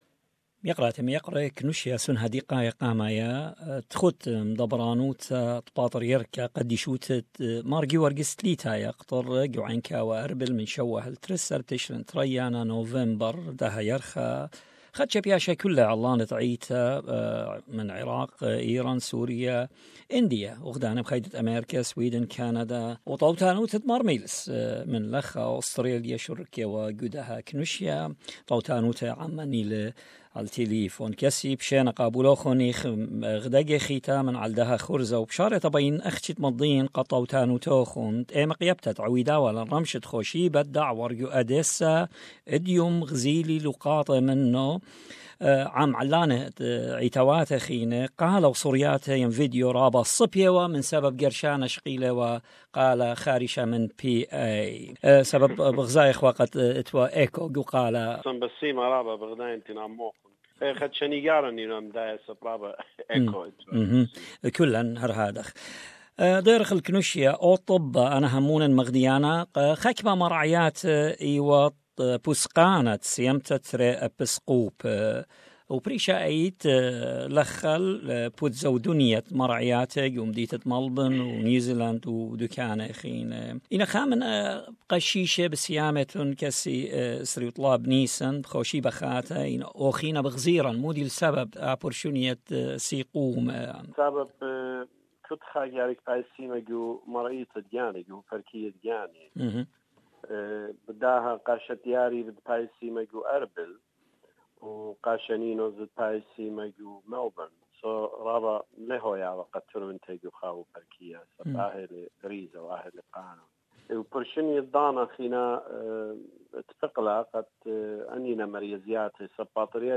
An exclusive interview with His Beatitude Mar Meelis Zaia, Metropolitan of Australia, New Zealand and Lebanon, discussing the importance of the Assyrian Church of the East's last Synod resolutions.